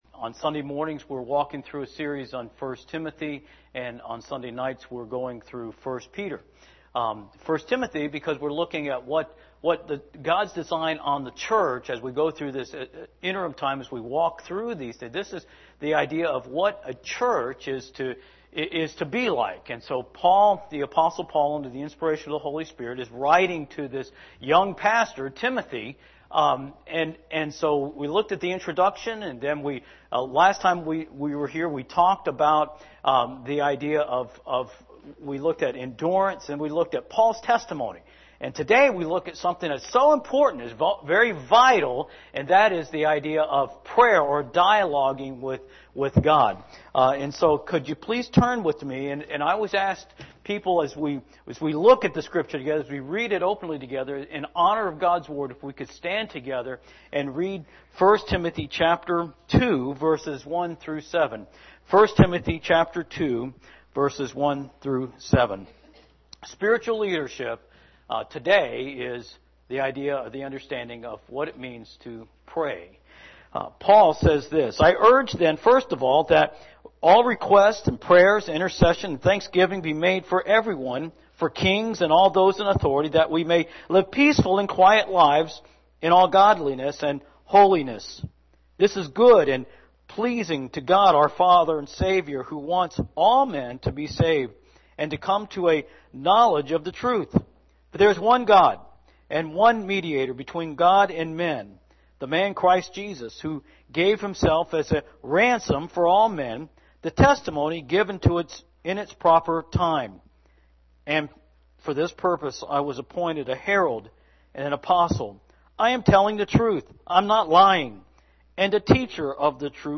sermon6-23-19am.mp3